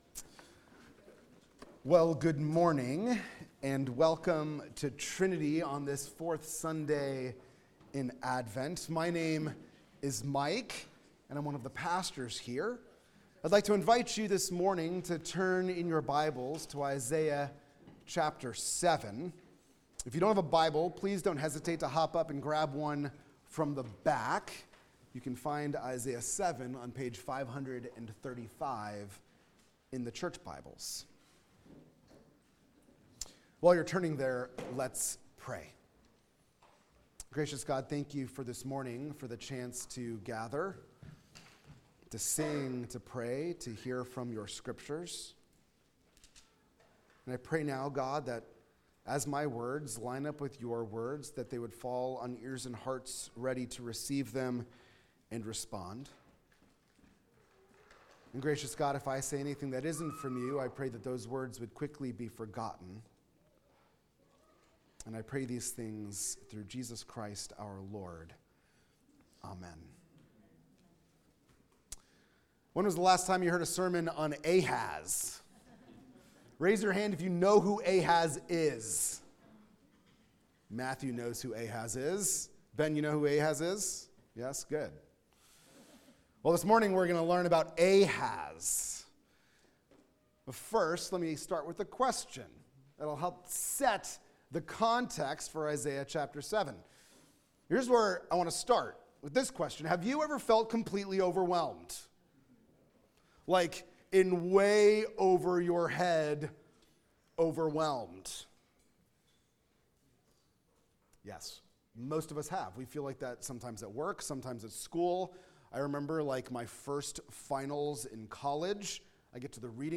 This Advent sermon